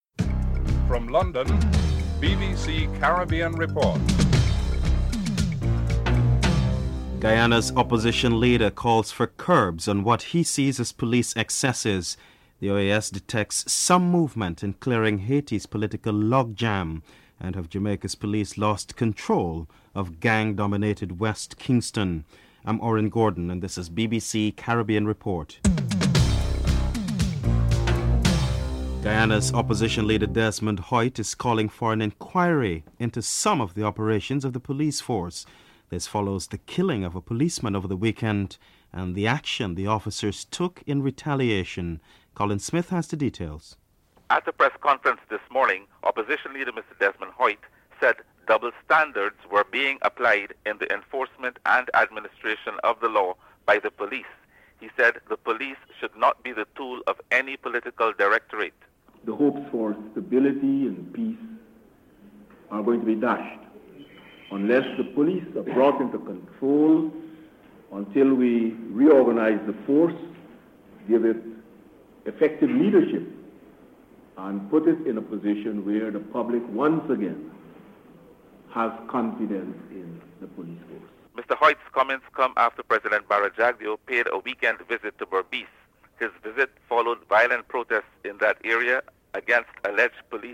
3. OAS detects some movement in clearing Haiti's political lock jam. Dame Eugenia Charles and OAS Deputy Secretary General Luigi Einaudi are interviewed (02:32-05:50)